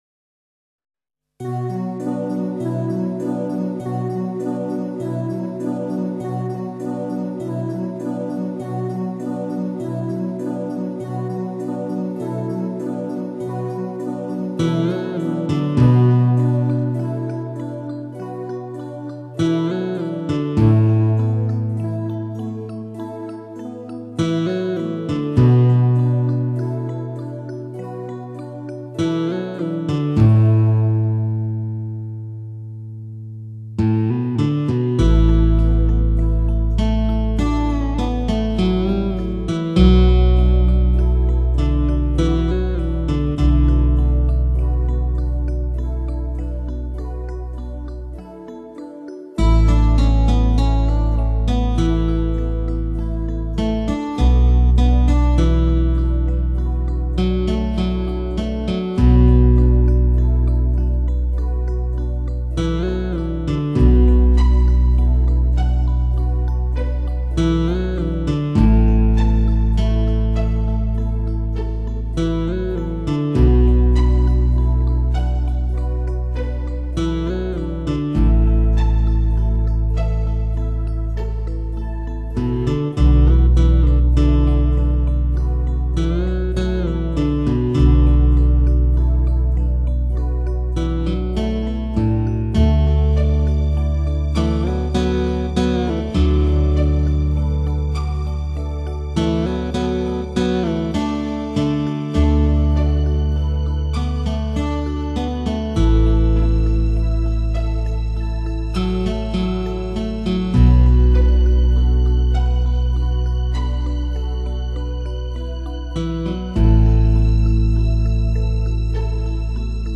古筝